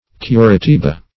Meaning of curitiba. curitiba synonyms, pronunciation, spelling and more from Free Dictionary.